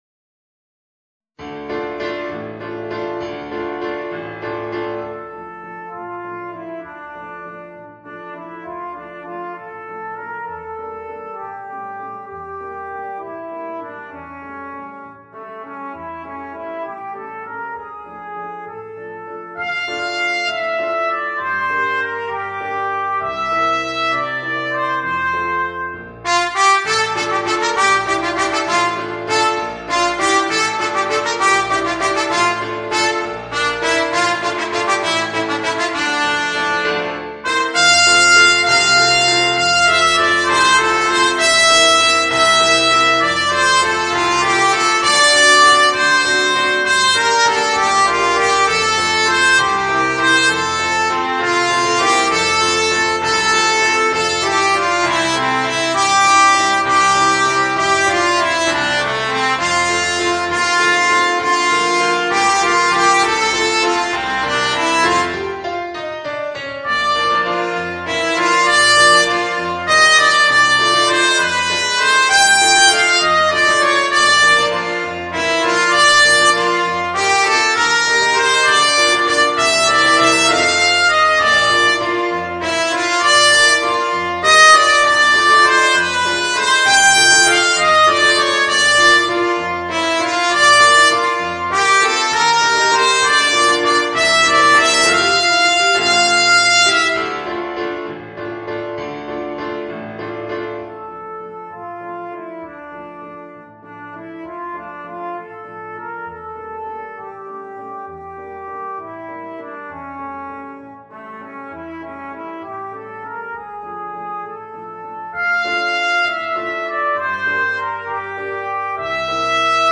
Voicing: Trumpet and Piano